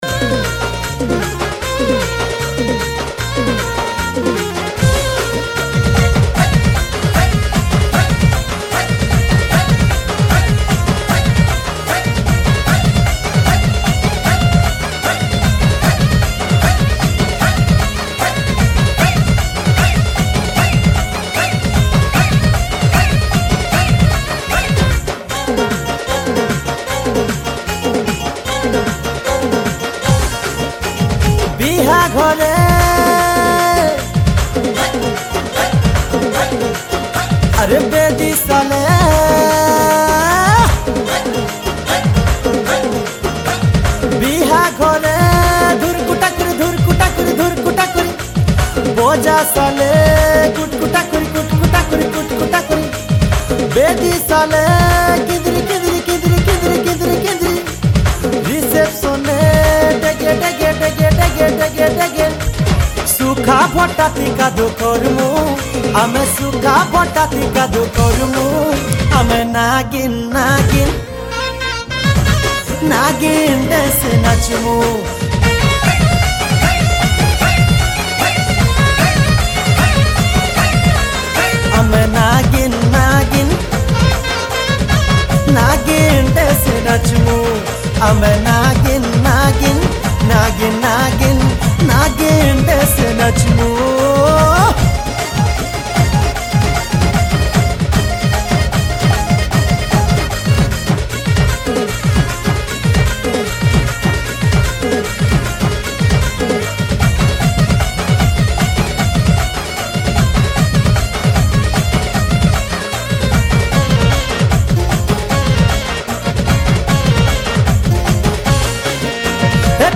New Sambalpuri Song 2025